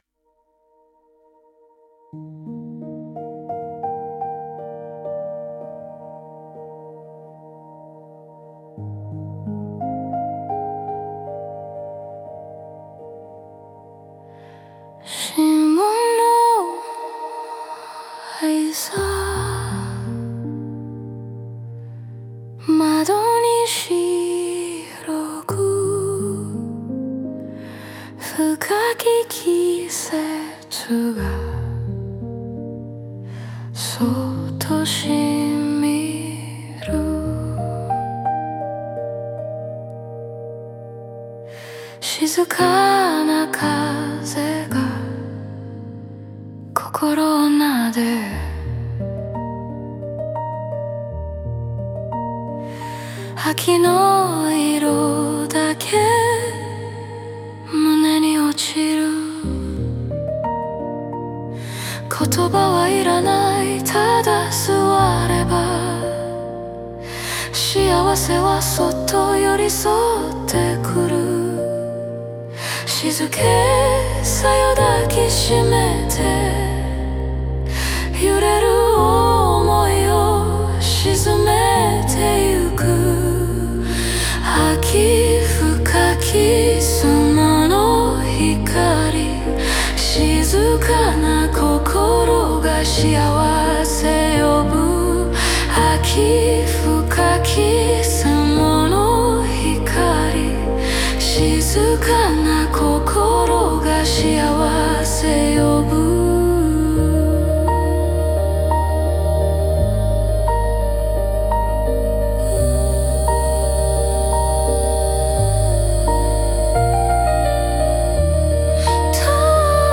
Il risultato è una traccia che non riempie: svuota.